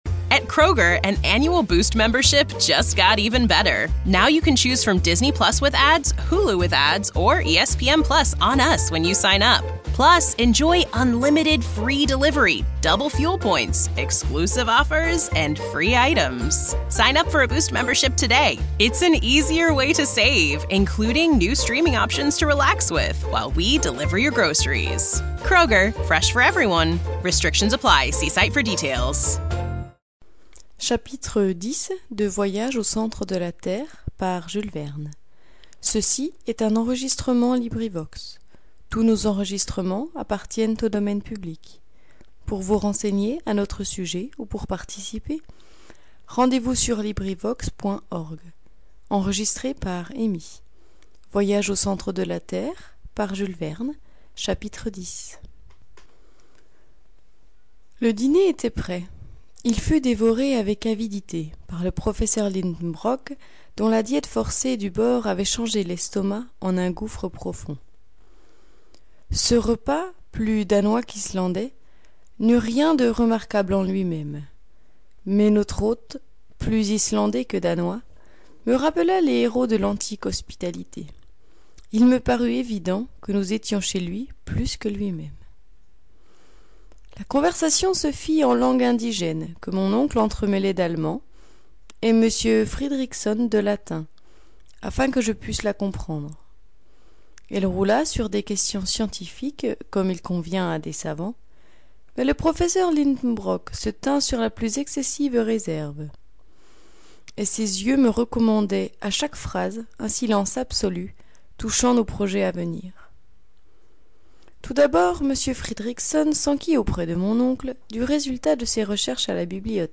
Livres Audio播客